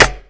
Snare3.wav